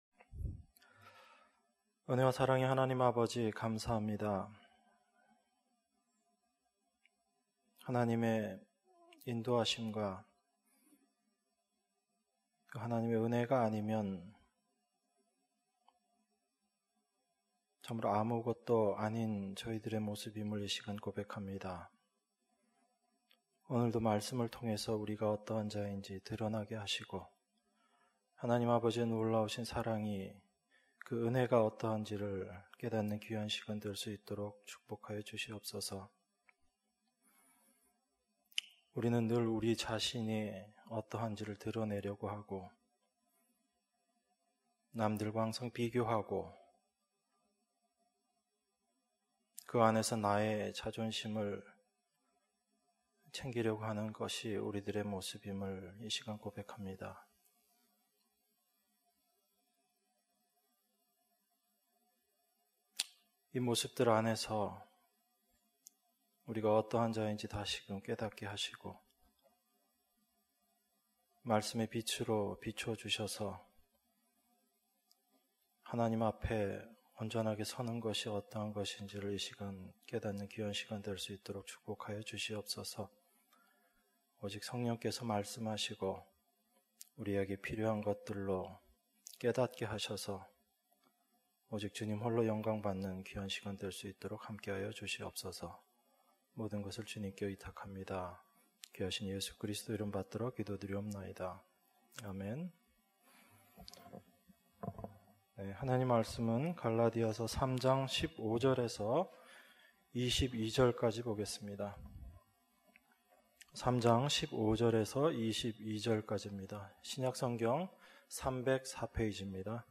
수요예배 - 갈라디아서 3장 15절-22절